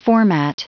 Prononciation du mot format en anglais (fichier audio)
Prononciation du mot : format